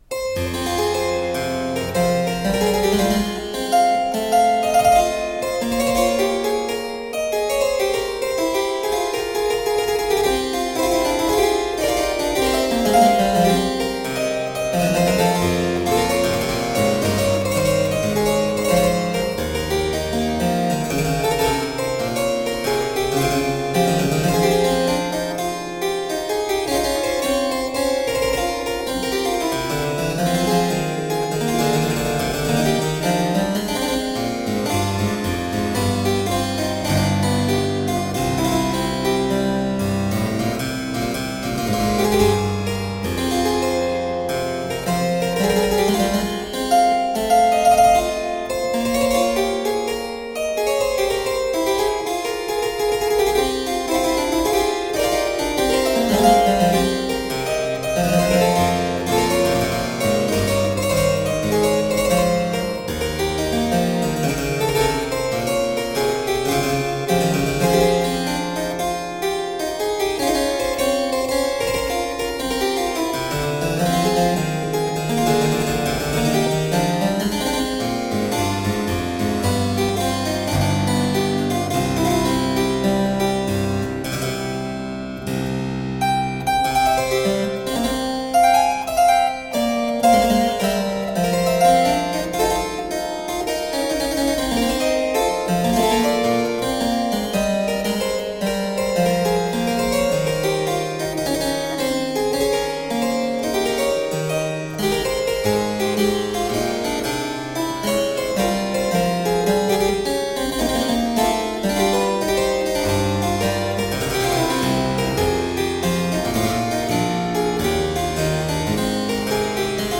Deeply elegant harpsichord.